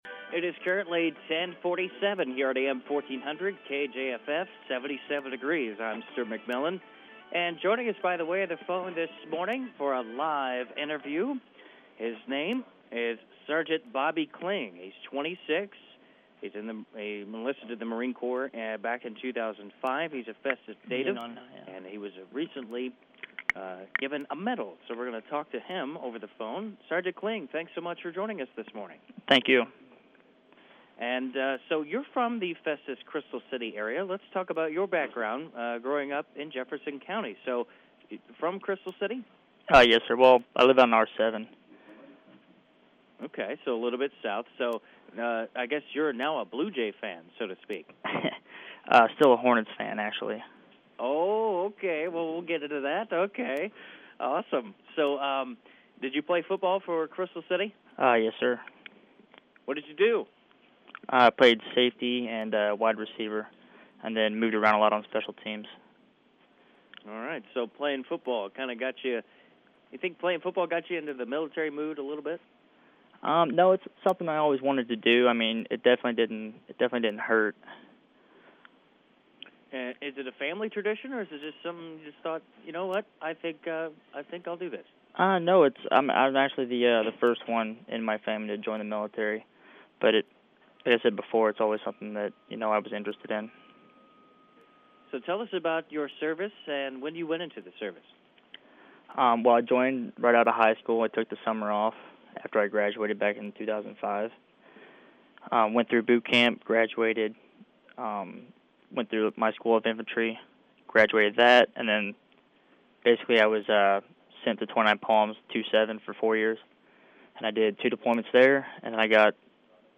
talks to a KJFF-AM reporter about why he was awarded a Purple Heart medal.